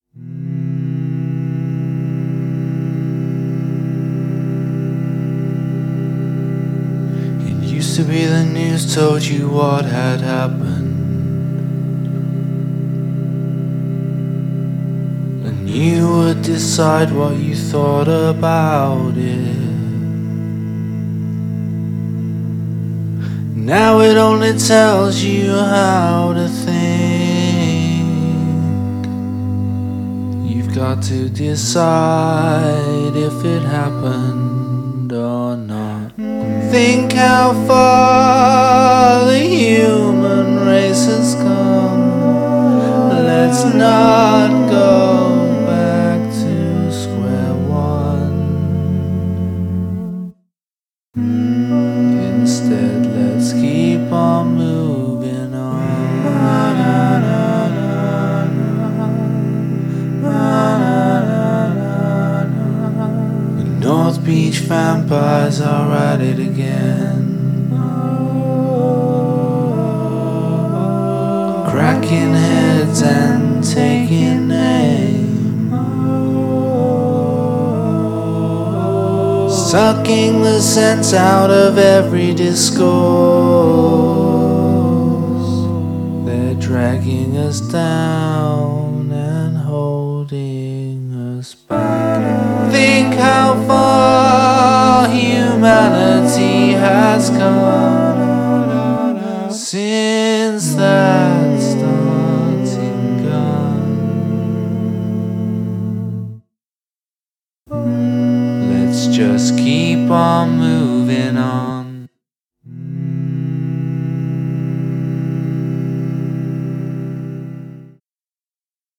Include an a cappella section